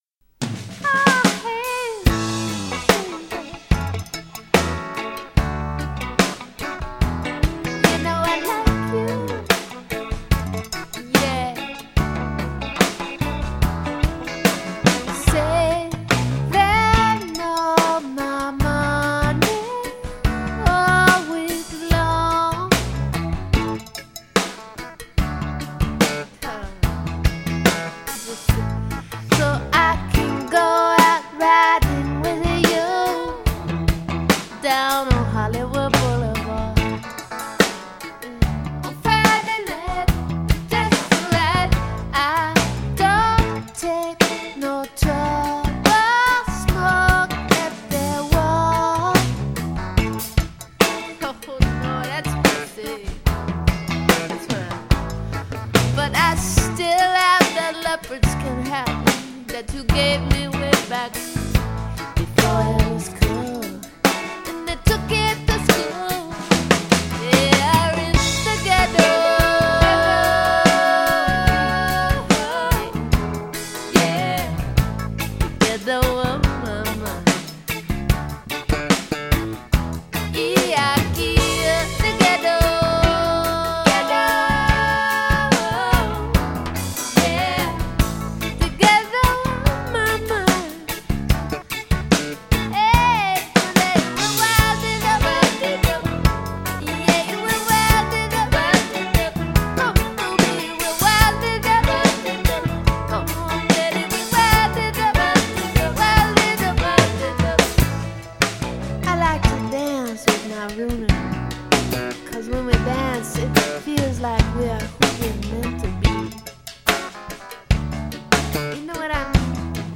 I think the percussion is particularly tasty in this one.